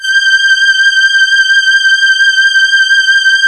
SYN BOWSTR5.wav